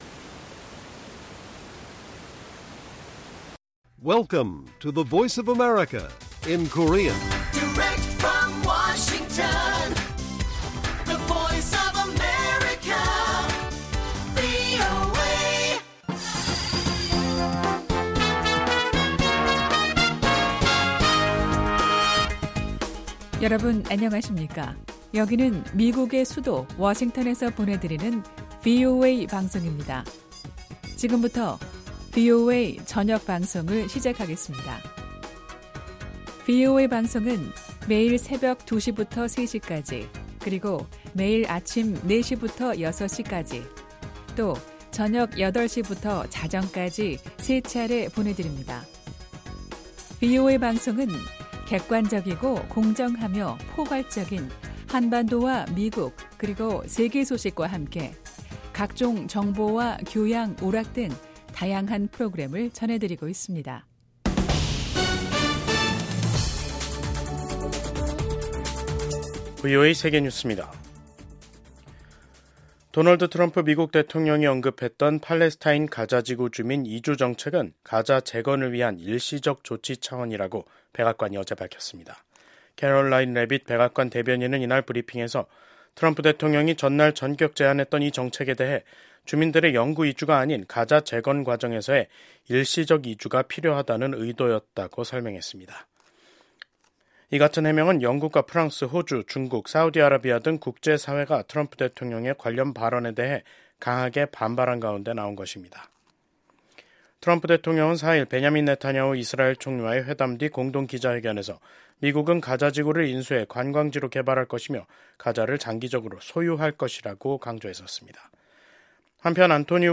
VOA 한국어 간판 뉴스 프로그램 '뉴스 투데이', 2025년 2월 6일 1부 방송입니다. 미국의 도널드 트럼프 행정부 출범으로 냉랭했던 북중 관계에 일정한 변화가 나타날 수 있다는 관측이 제기됩니다. 미국 의회에서 코리아코커스 공동의장을 맡고 있는 의원들이 트럼프 행정부가 들어서면서 한국이 미국과 에너지 협력을 확대하는 데 유리한 환경이 조성됐다는 초당적인 입장을 밝혔습니다.